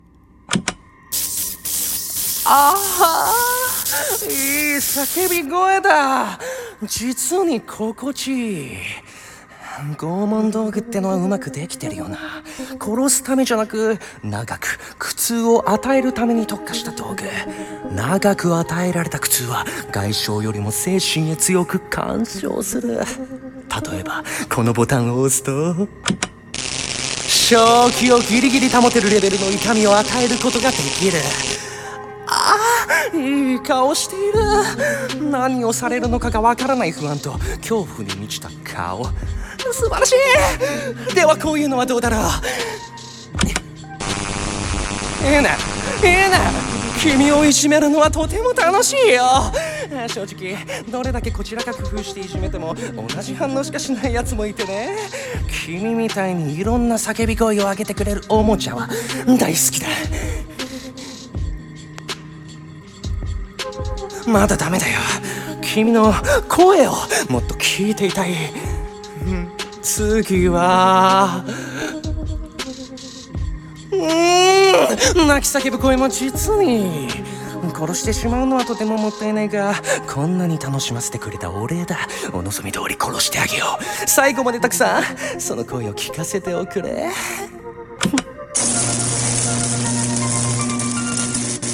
声劇台本】死を求める苦痛は拷問と共に